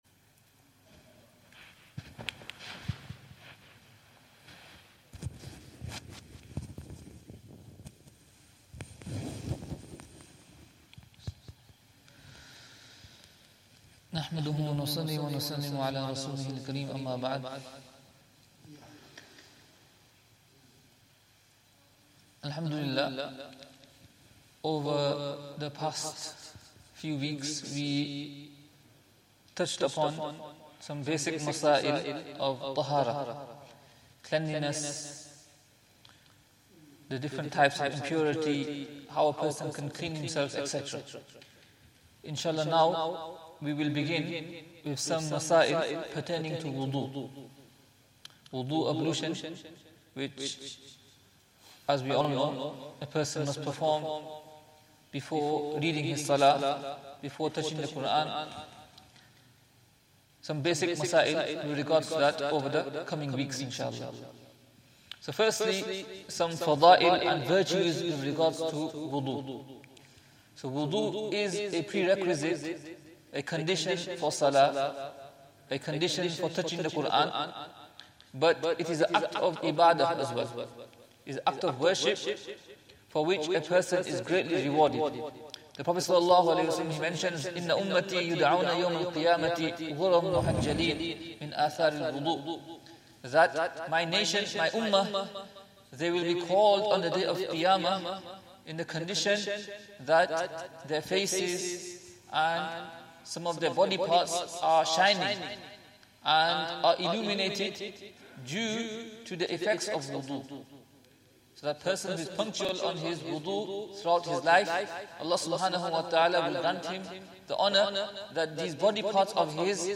Masail Halaqah
Zakariyya Jaam'e Masjid, Bolton